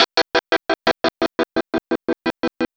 Track 02 - Guitar Pulse.wav